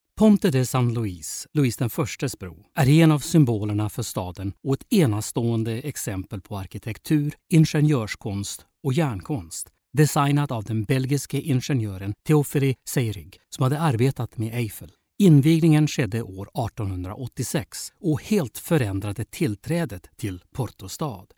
Swedish male voice overs
Swedish male voice over